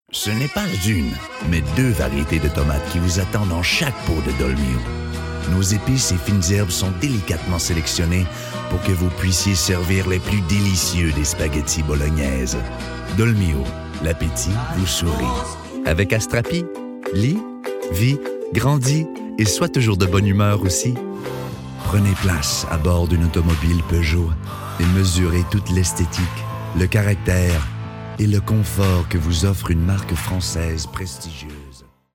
French-Canadian, Male, Home Studio, 30s-40s
Home Studio Read